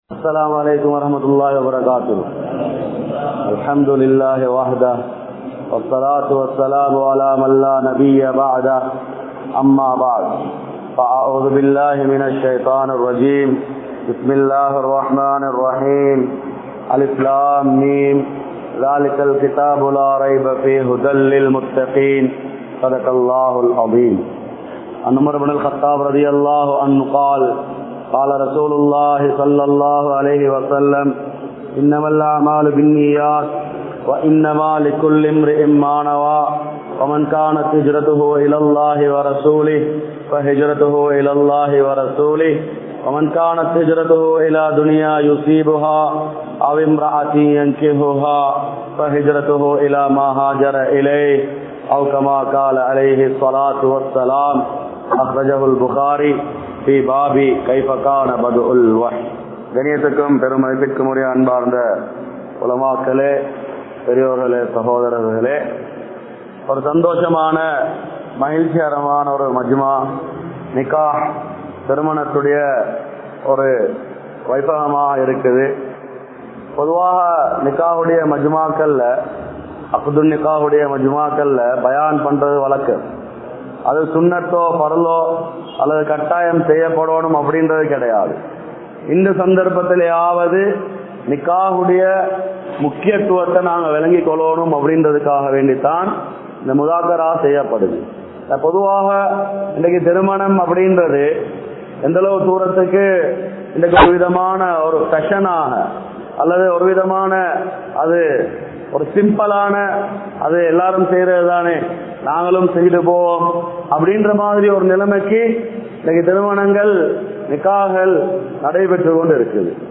Thirumanaththai Kastamaakkaatheerhal (திருமணத்தை கஷ்டமாக்காதீர்கள்) | Audio Bayans | All Ceylon Muslim Youth Community | Addalaichenai
Muhideen Jumua Masjith